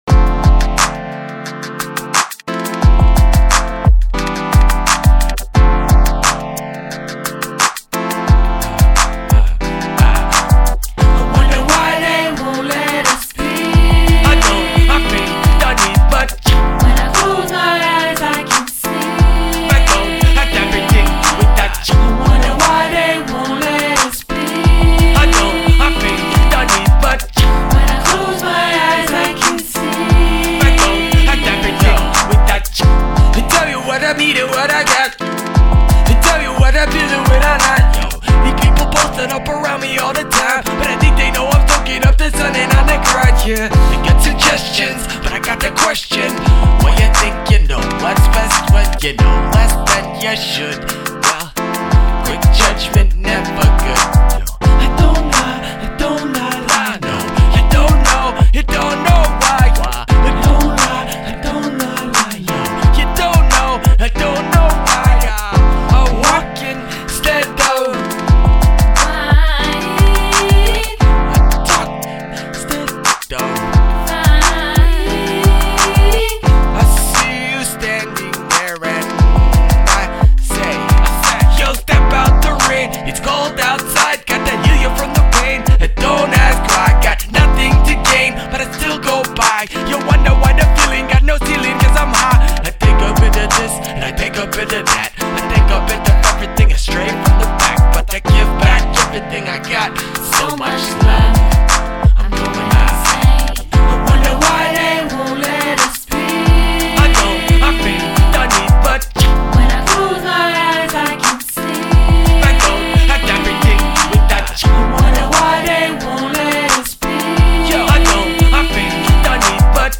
Recorded at Ground Zero Studios and Seattle Chop Shop